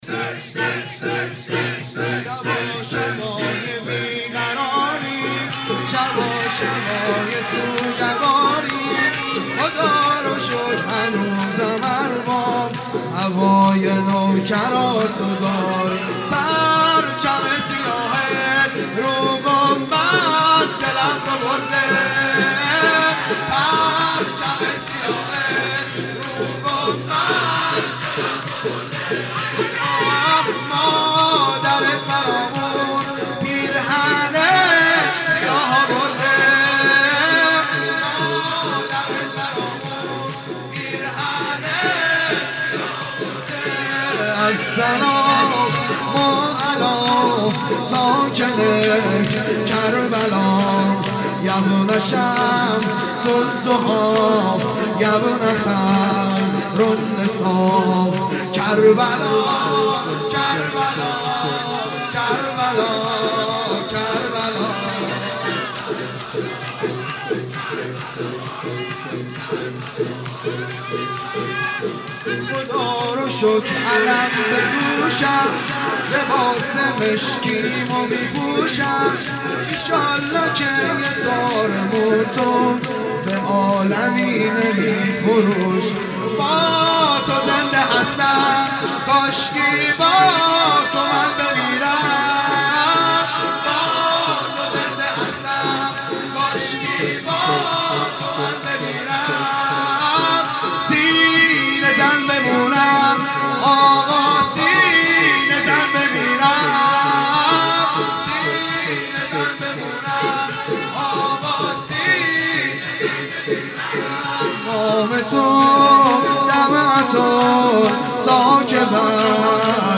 شور
پرچم سیاهت رو گنبد|شب دوم محرم 96